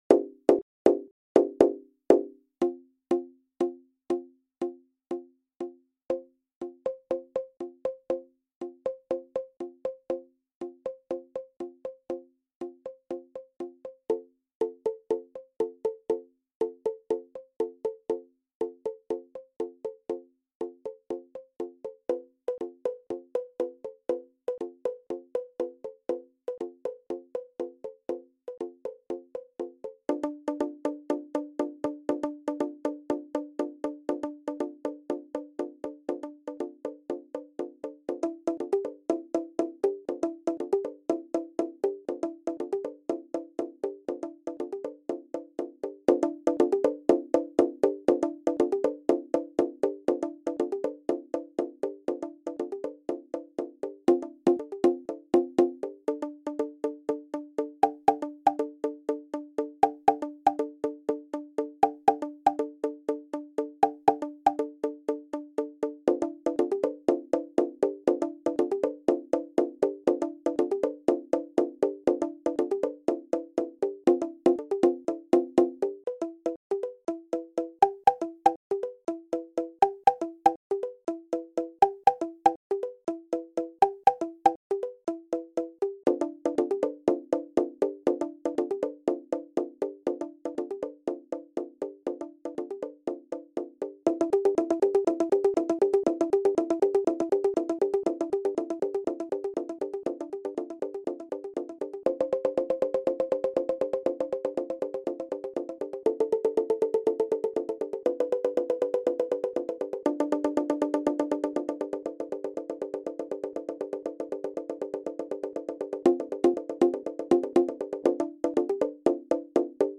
MIDI audio